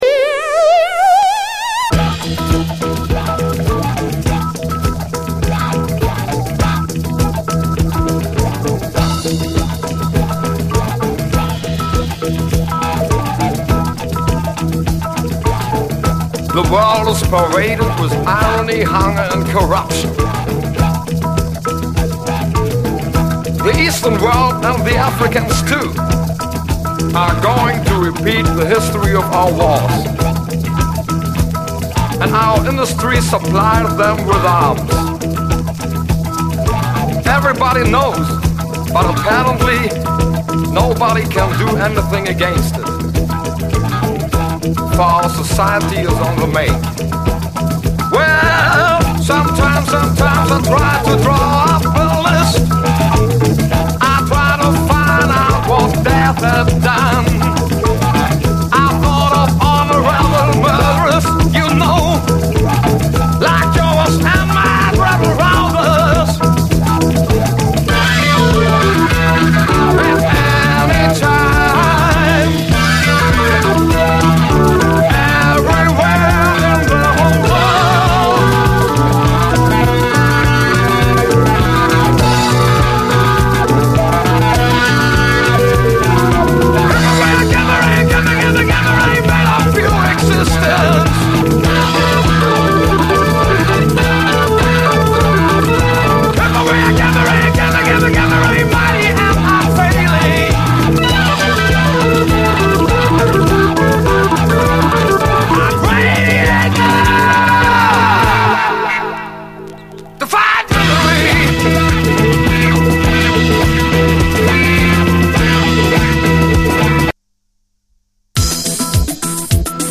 60’SチカーノR&B〜70’Sメロウ・チカーノ・ファンク〜90’Sまで、いずれもオリジナルは相当レアなシングル音源集。